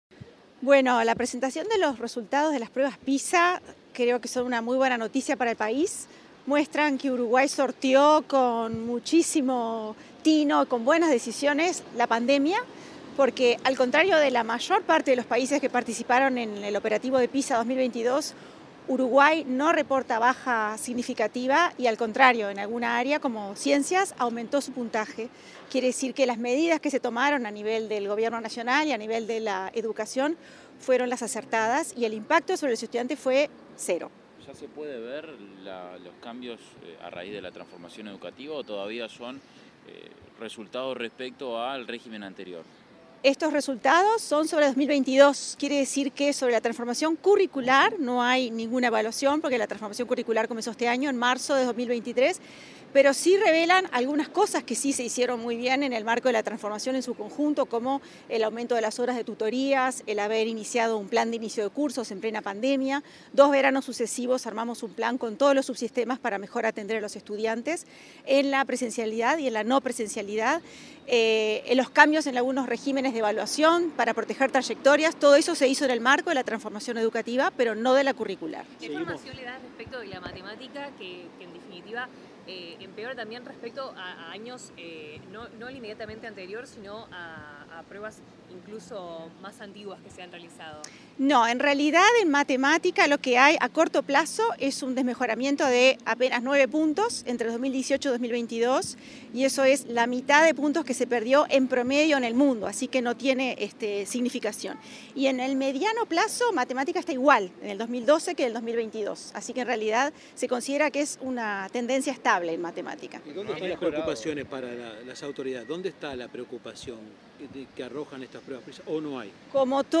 Declaraciones de la directora ejecutiva de Políticas Educativas de la ANEP, Adriana Aristimuño
Declaraciones de la directora ejecutiva de Políticas Educativas de la ANEP, Adriana Aristimuño 05/12/2023 Compartir Facebook X Copiar enlace WhatsApp LinkedIn Tras la presentación del informe de los resultados de las pruebas PISA 2022, este 5 de diciembre, la directora ejecutiva de Políticas Educativas de la Administración Nacional de Educación Pública (ANEP), Adriana Aristimuño, realizó declaraciones a la prensa.